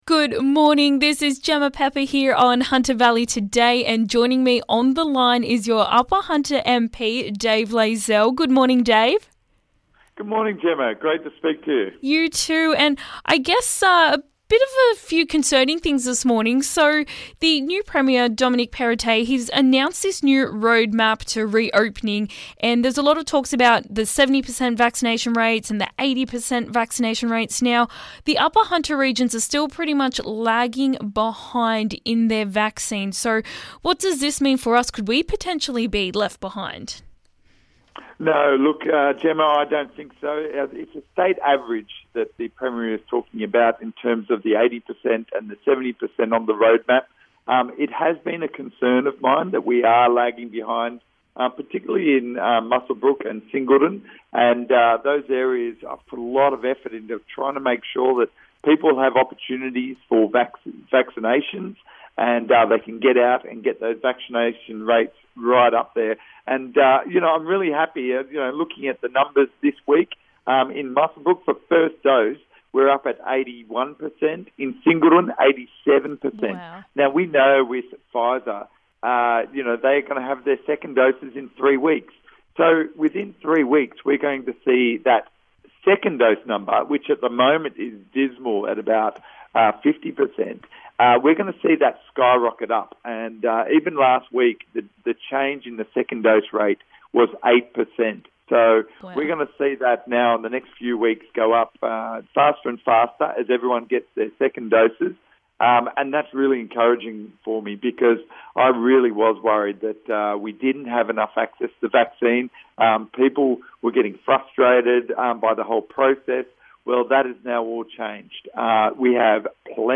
Interview with Upper Hunter MP Dave Layzell discussing reopening roadmap, vaccinations, Nationals leadership and tourism in the Hunter